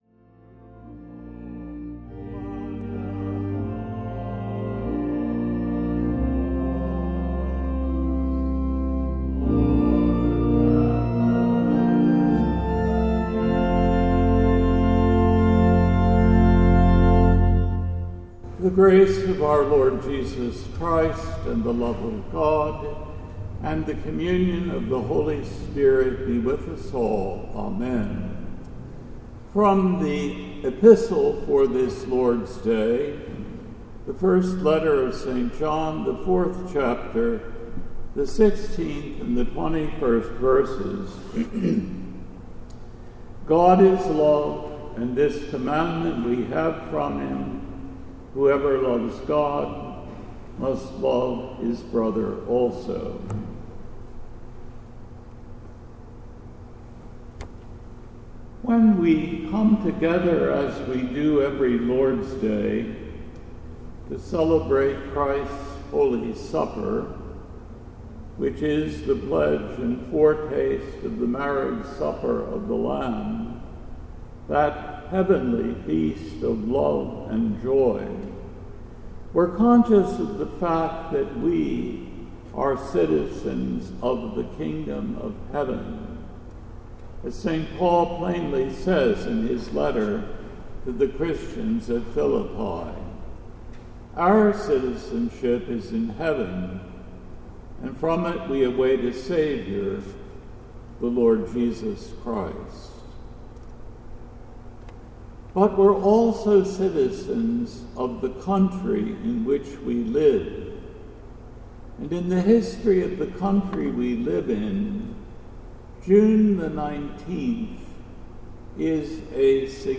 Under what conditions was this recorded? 1st Sunday after Trinity